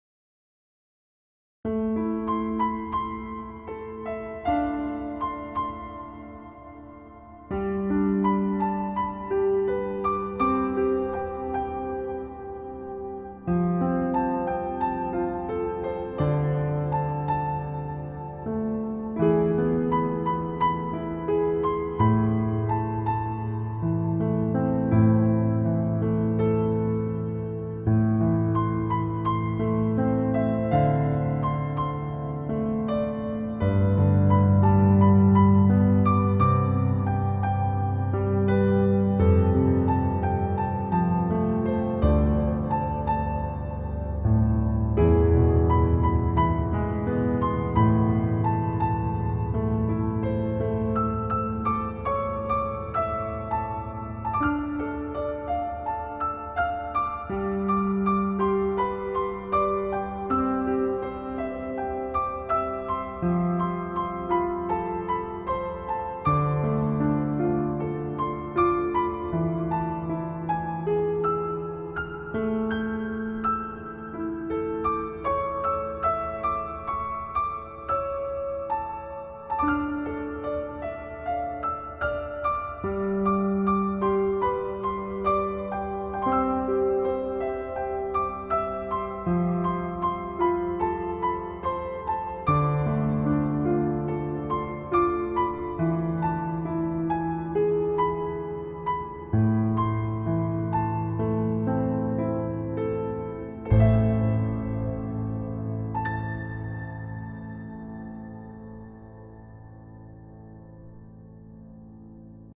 ПЕЧАЛЬНАЯ